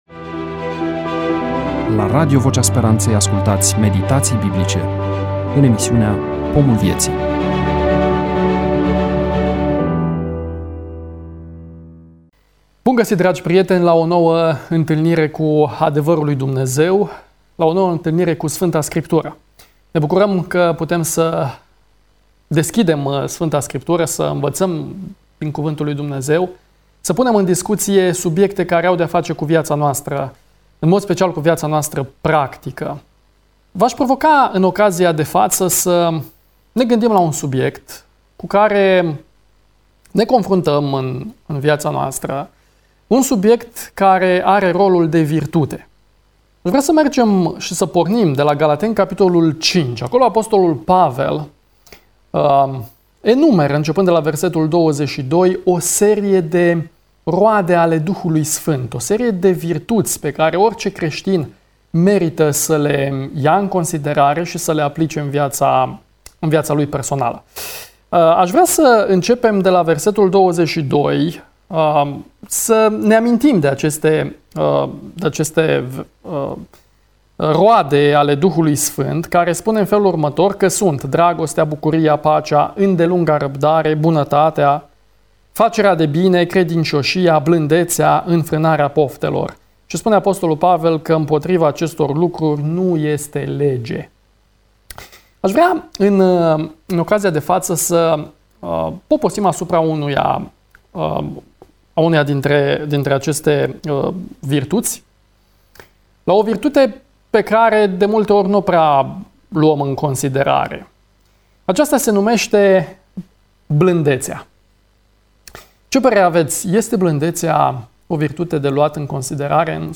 EMISIUNEA: Predică DATA INREGISTRARII: 02.10.2024 VIZUALIZARI: 230